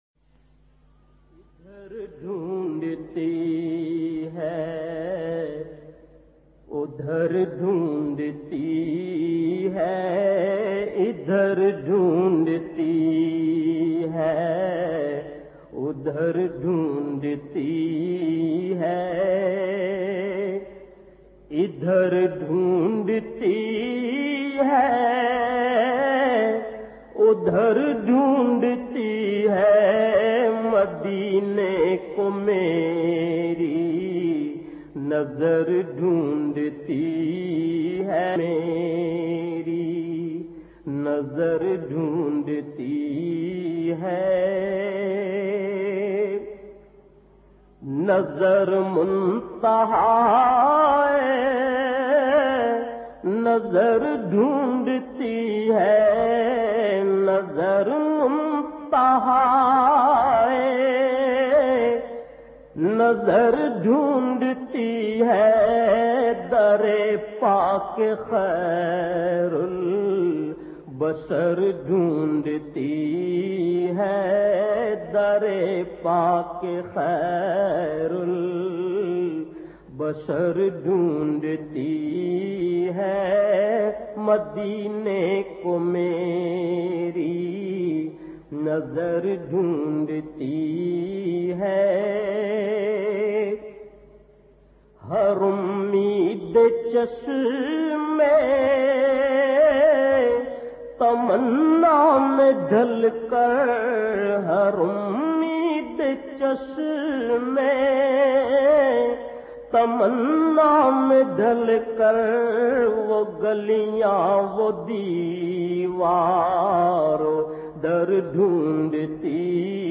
a Heart-Touching Voice